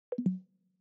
Звуки MacBook, iMac
Звук освежающего ветерка